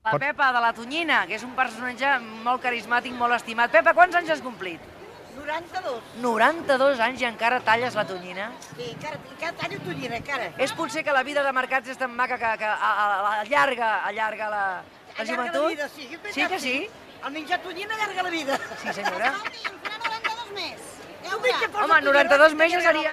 Conversa amb una peixatera en un dels mercats de Barcelona
Entreteniment
FM